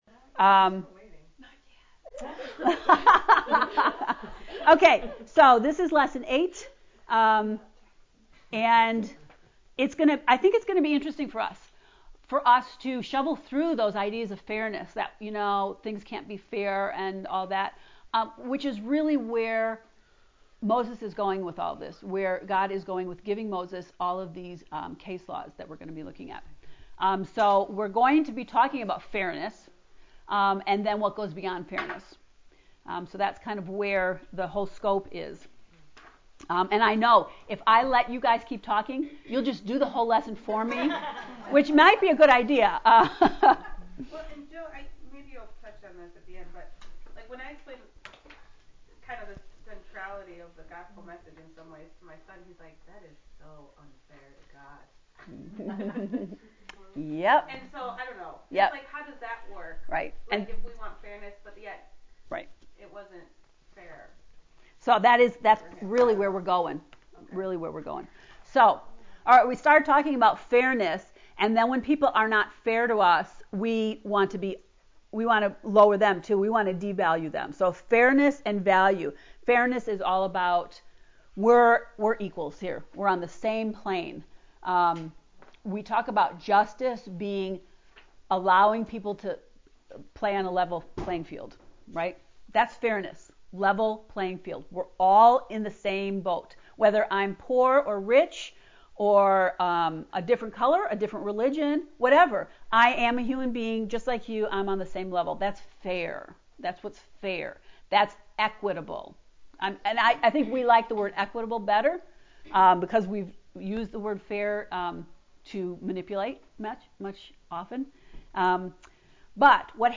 To listen to the Exodus 8 lecture ” Listening to Hear the Fairness of God” click below: